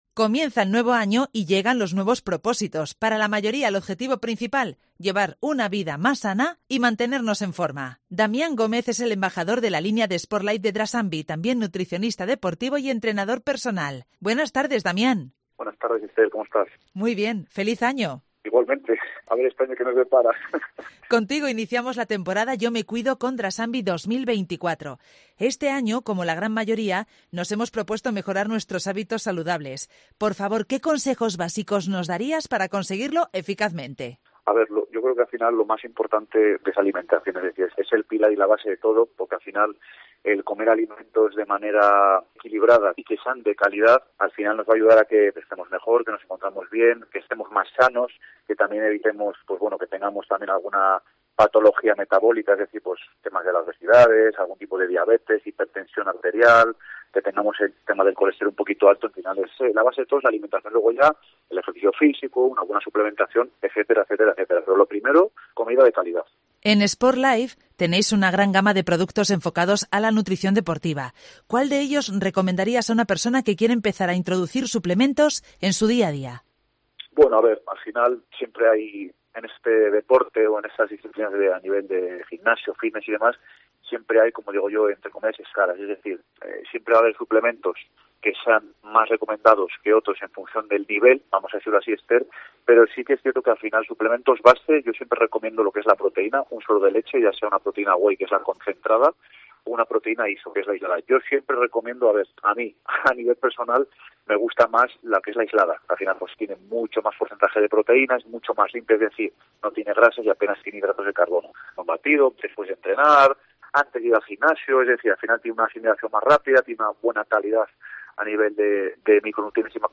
En Mediodía en COPE León